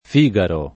Figaro [ f &g aro ]